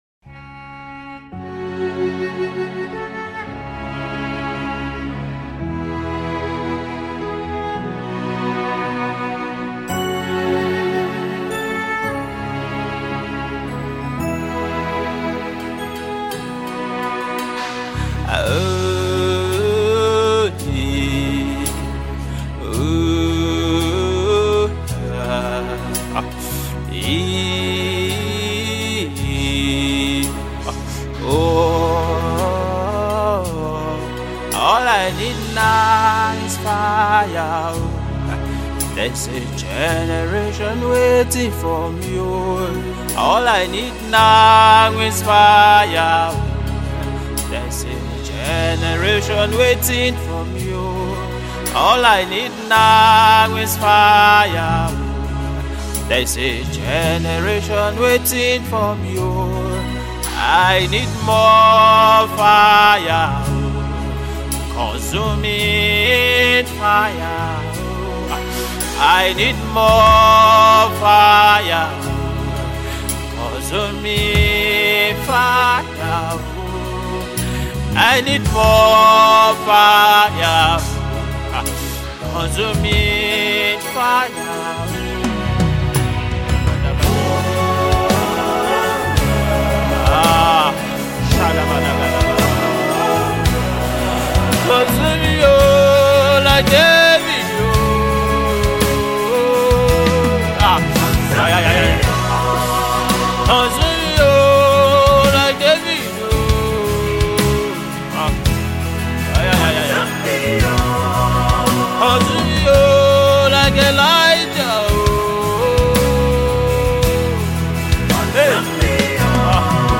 heartfelt gospel worship song